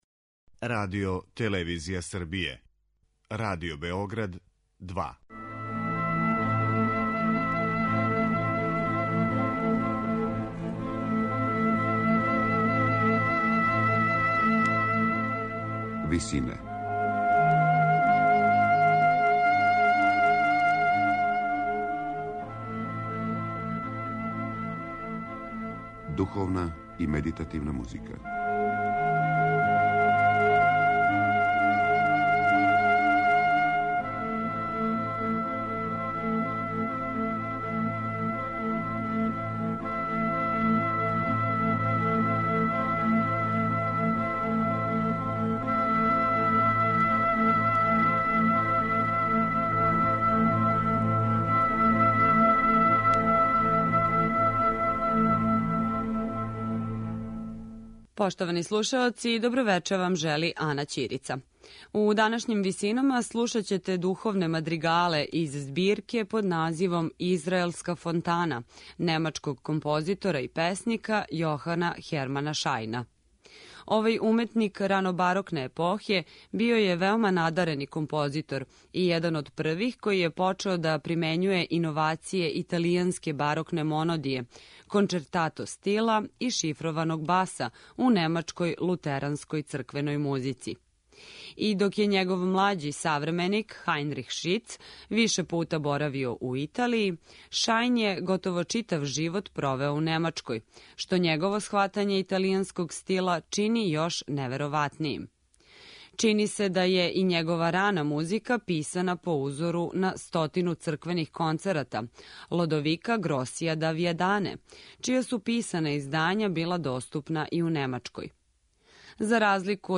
Емисија духовне музике
На крају програма, у ВИСИНАМА представљамо медитативне и духовне композиције аутора свих конфесија и епоха.
У данашњој емисији слушаћете одабране духовне мадригале из збирке „Израелска фонтана" немачког композитора и песника Јохана Хермана Шајна.